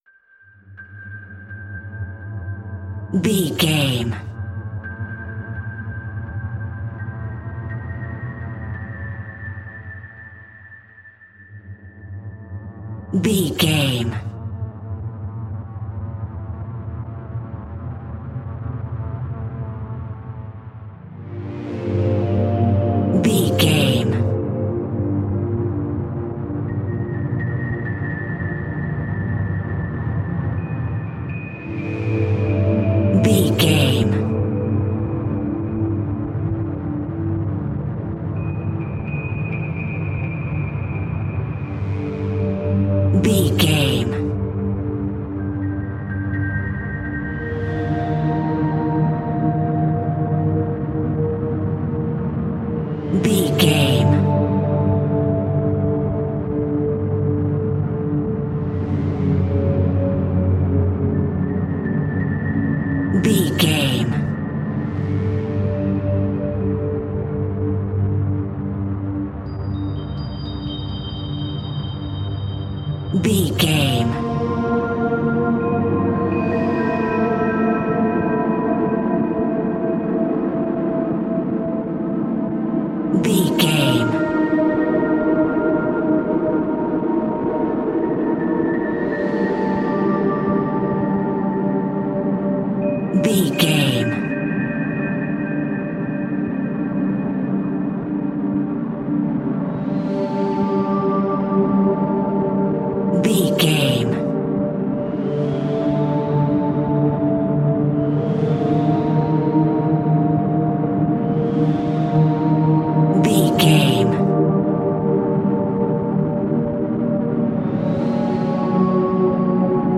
Aeolian/Minor
ominous
dark
haunting
eerie
synthesiser
strings
percussion
instrumentals
horror music